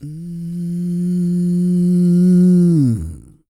E-CROON 3035.wav